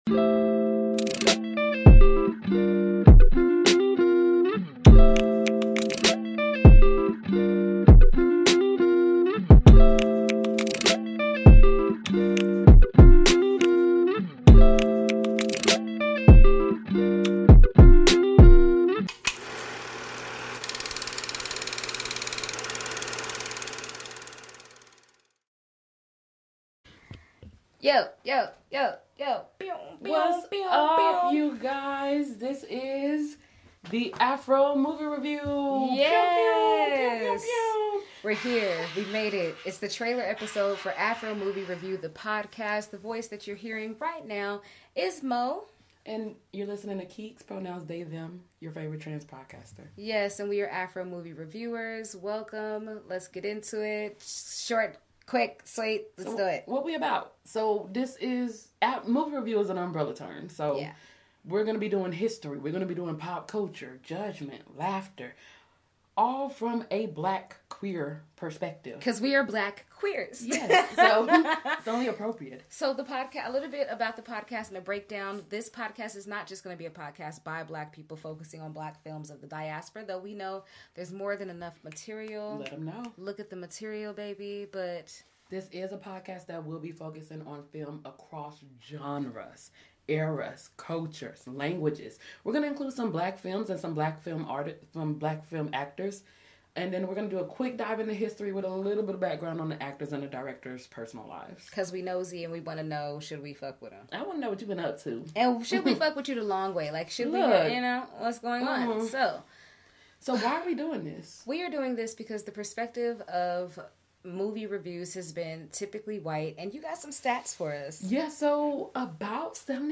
Trailer: